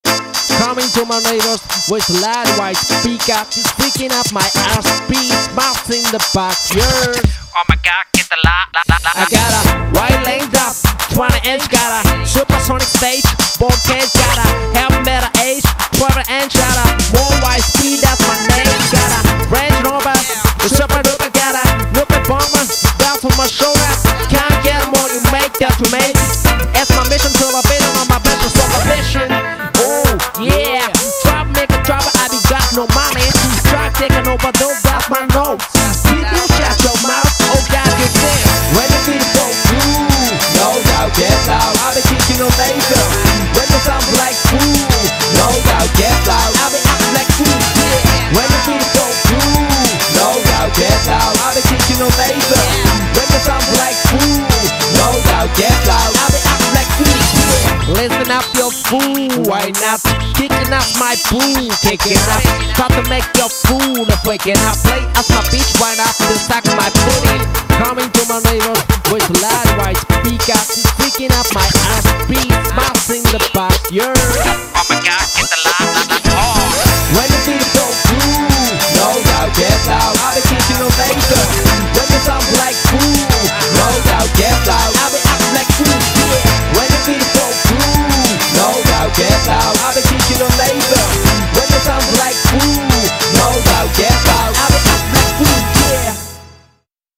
BPM100
Audio QualityPerfect (High Quality)
It's Engrish lyrics are charmingly groovy.